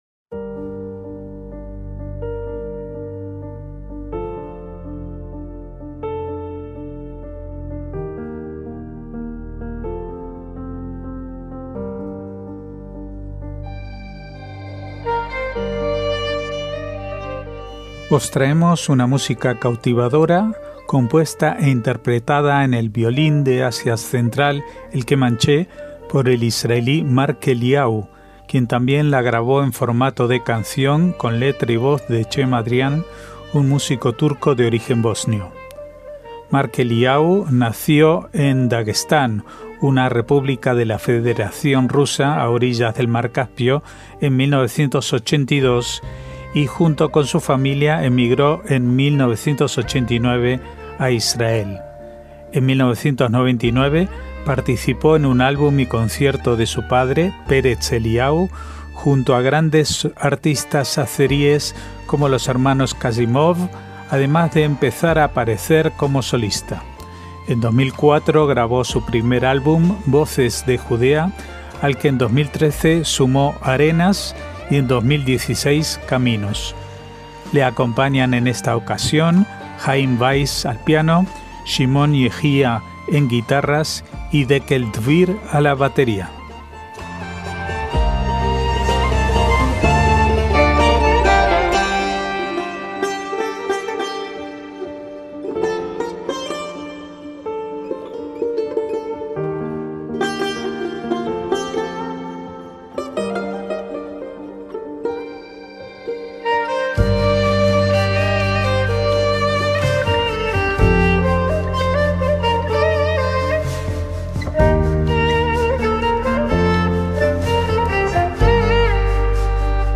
MÚSICA ISRAELÍ
al piano
en guitarras